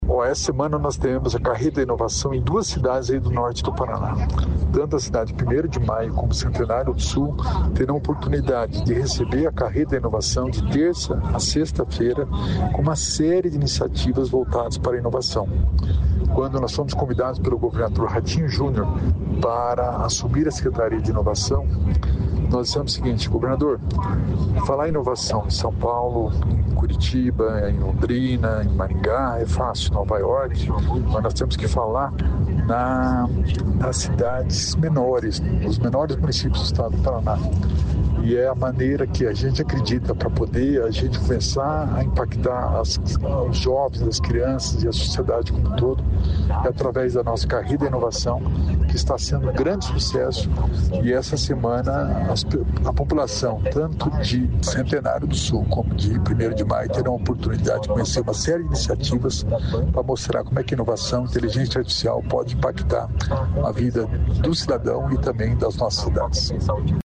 Sonora do secretário da Inovação, Alex Canziani, sobre a Carreta da Inovação